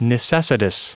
Prononciation du mot : necessitous
necessitous.wav